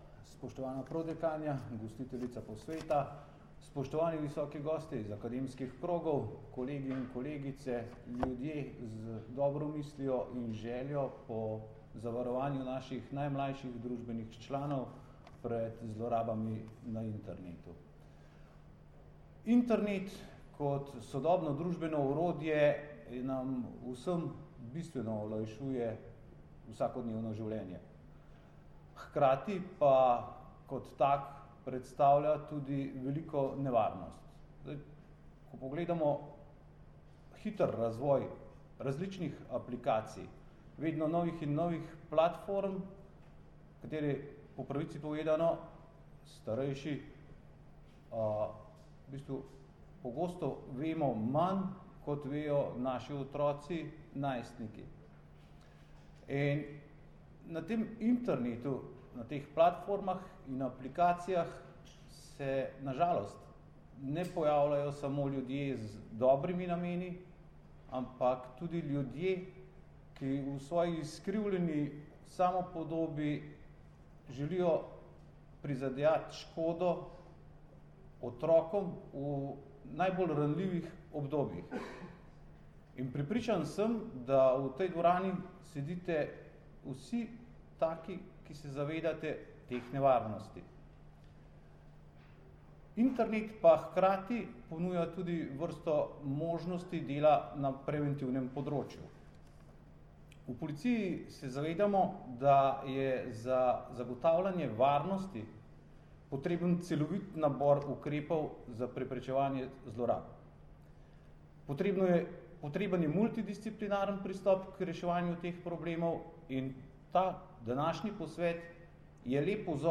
Na Fakulteti za družbene vede je včeraj, 27. septembra 2018, potekal osmi posvet na temo zlorab otrok na internetu z naslovom Obravnava e-zlorab otrok: iz teorije v prakso.
Zvočni posnetek nagovora mag. Boštjana Lindava, (mp3)
direktorja Uprave kriminalistične policije na Generalni policijski upravi